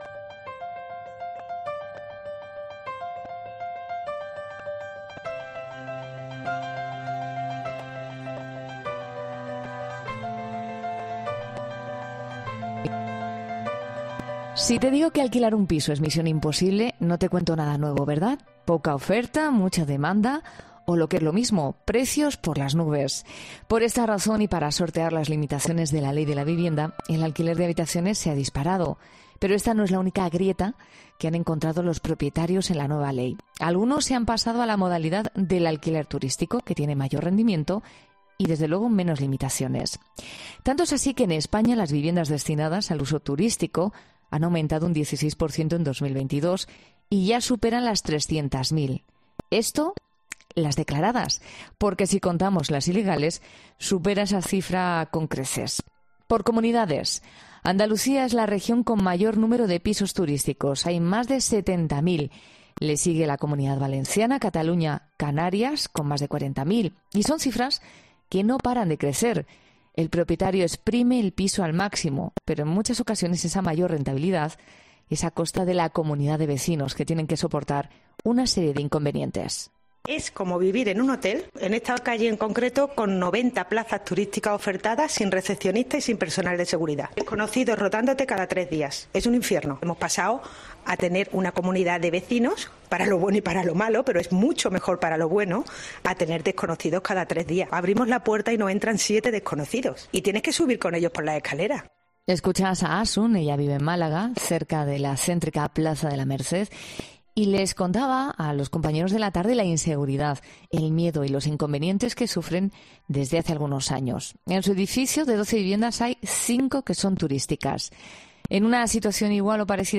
En 'Fin de Semana' conocemos, en la voz de muchos expertos, qué supone una sentencia del Tribunal Supremo que avala el veto de vecinos a este tipo de viviendas